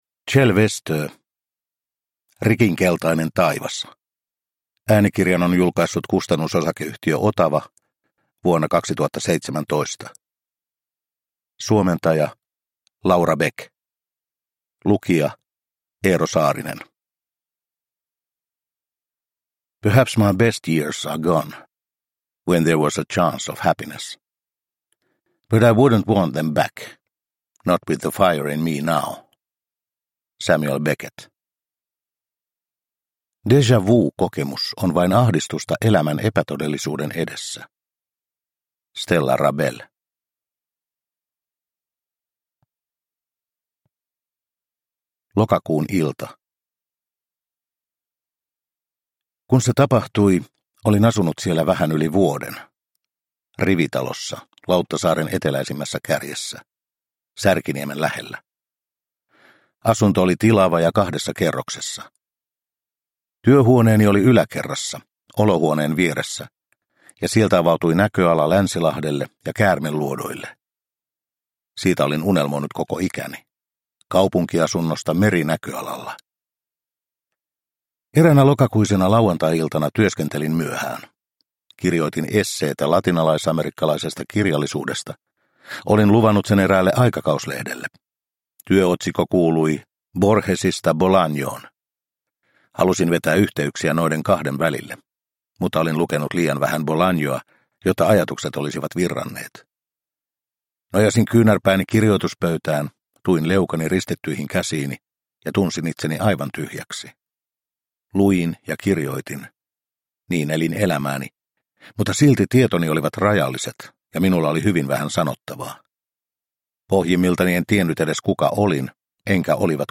Rikinkeltainen taivas – Ljudbok – Laddas ner